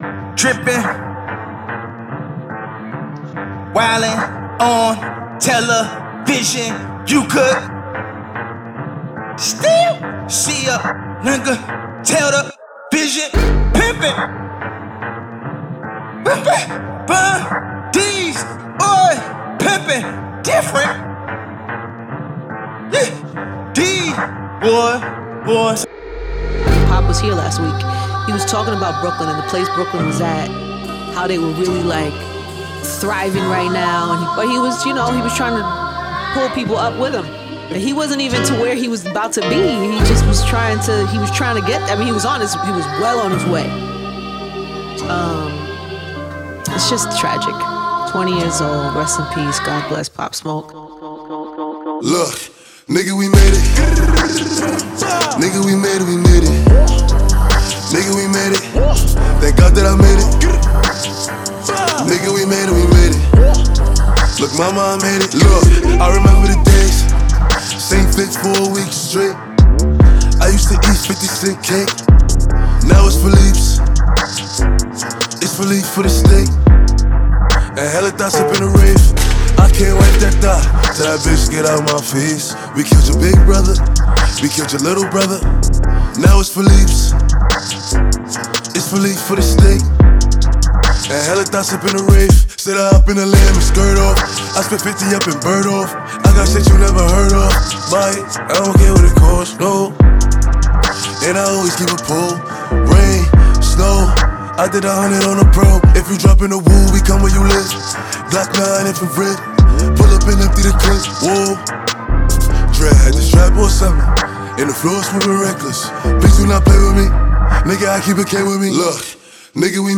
Hip-Hop Drill